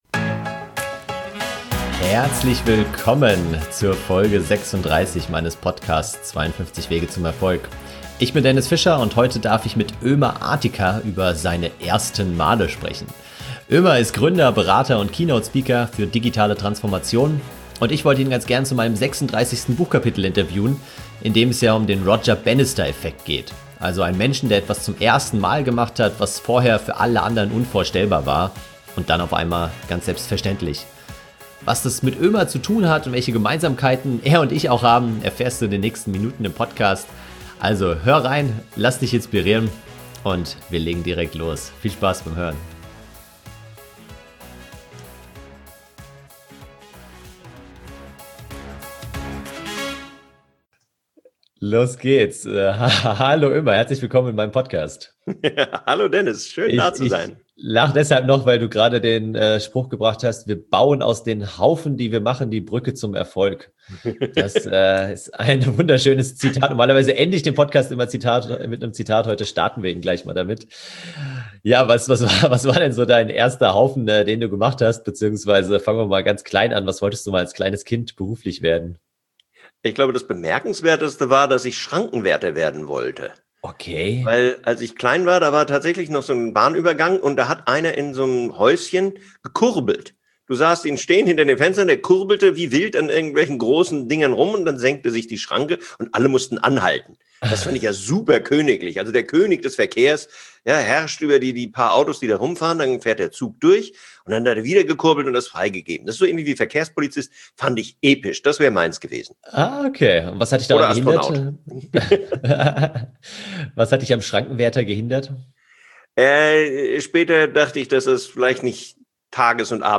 Berater und Keynote Speaker für Digitale Transformation und ich wollte ihn gerne zu meinem 36. Buchkapitel interviewen, in dem es ja um den Roger Bannister Effekt geht. Also einen Menschen, der etwas zum ersten Mal gemacht hat, was vorher für alle anderen unvorstellbar war.